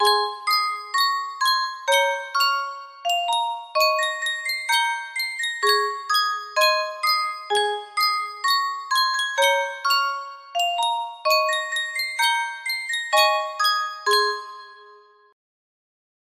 Sankyo Music Box - Rose Garden KP music box melody
Full range 60